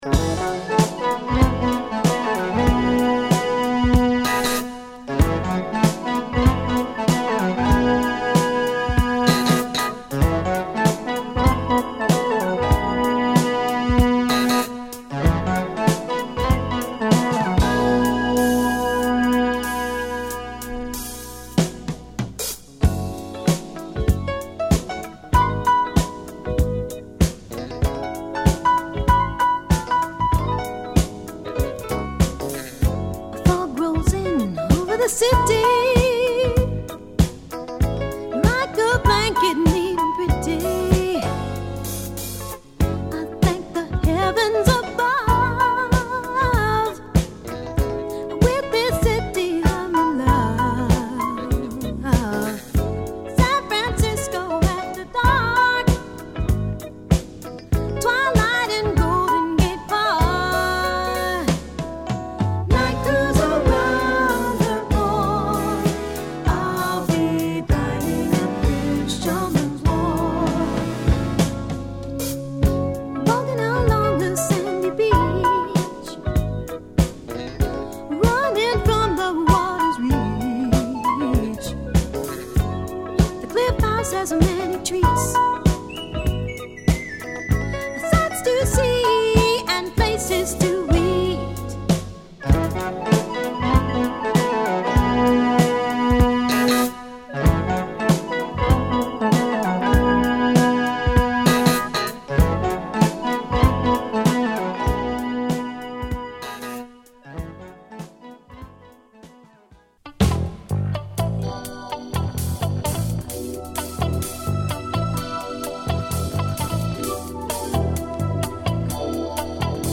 カリフォルニア出身のキーボーディスト
人気のメロウ・モダンソウルA1
ナイスなフュージョン〜モダンブギーA4